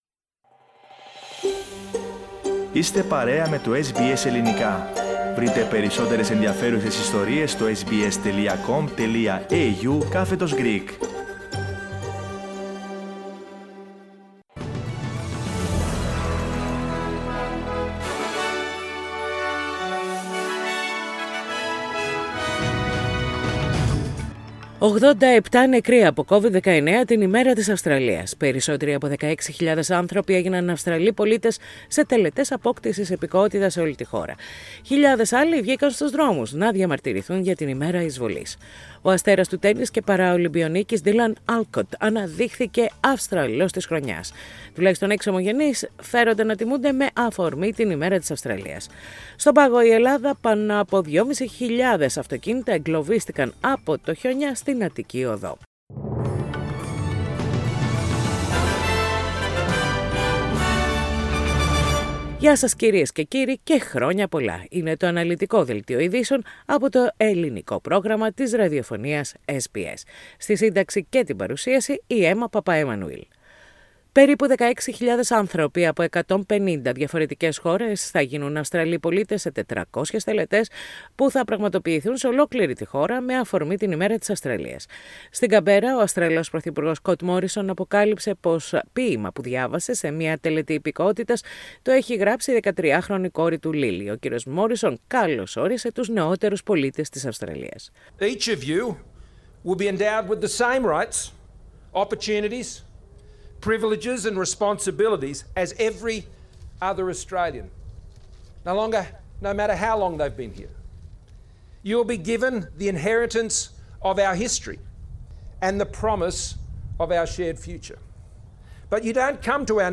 Core bulletin of Australia Day 2022, with the main news from Australia, Greece, Cyprus and the rest of the world.